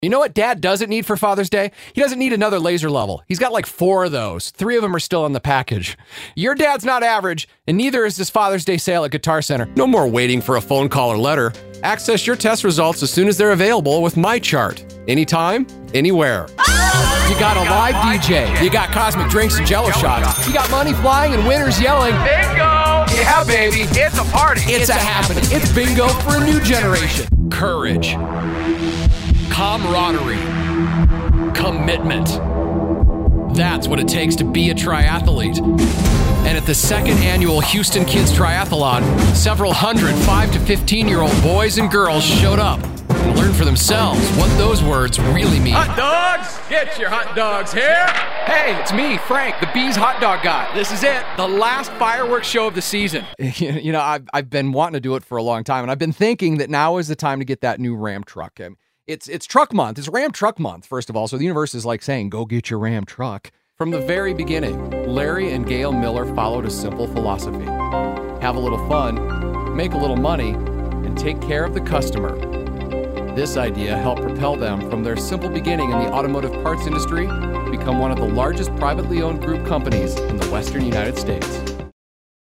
Assorted voiceover demos and radio airchecks
Commercial+VO+Demo.mp3